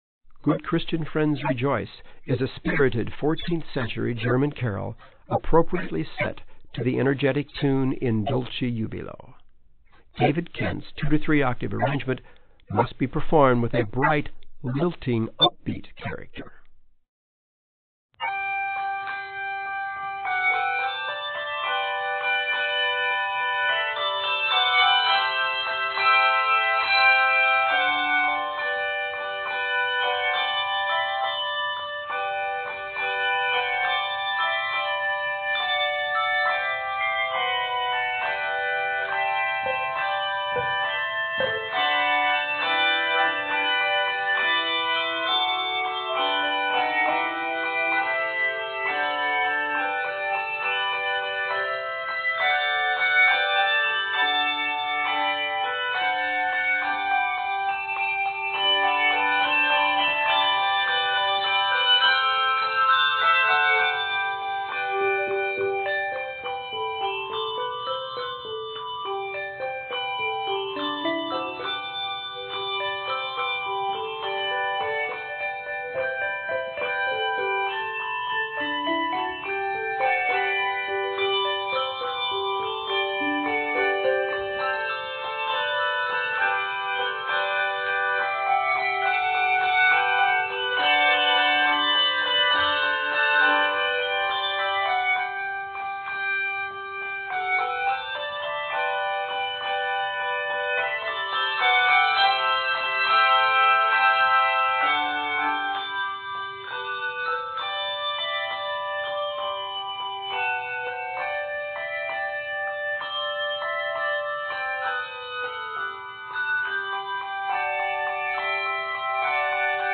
festive and energetic setting